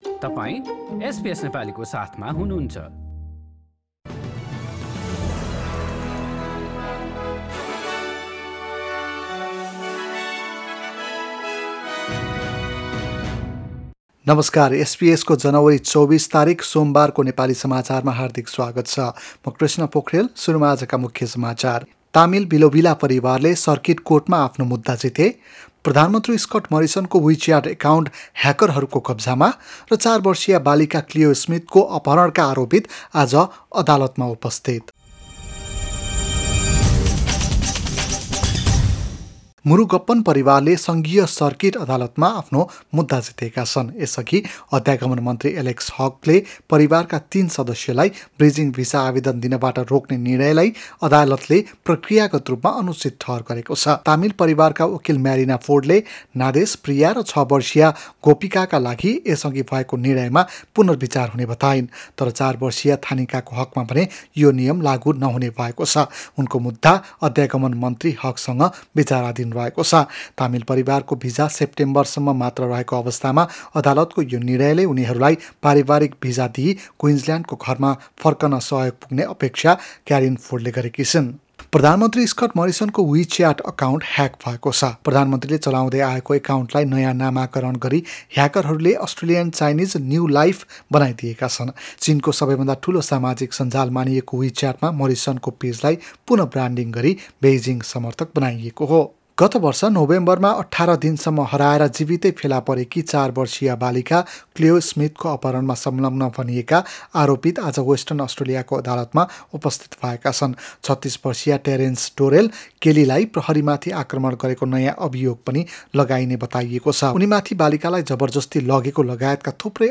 एसबीएस नेपाली अस्ट्रेलिया समाचार: सोमबार २४ जनवरी २०२२